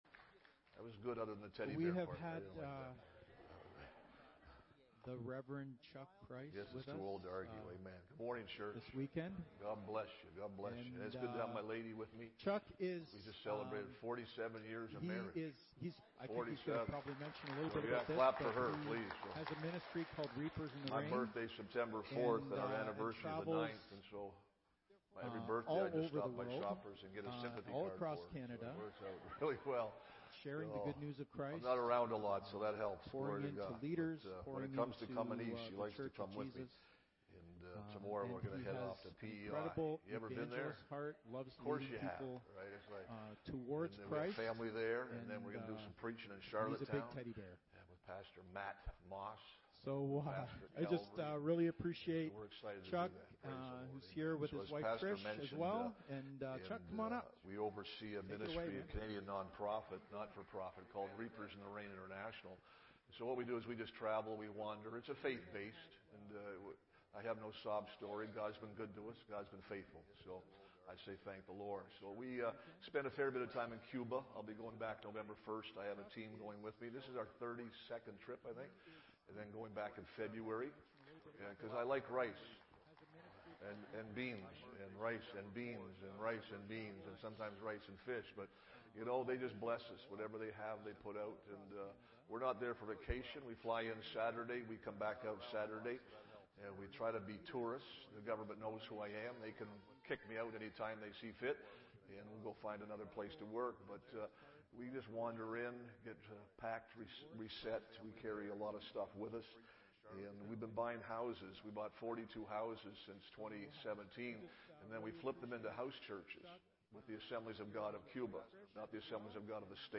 Listen to our weekly Sunday messages to help you grow in your walk with Jesus.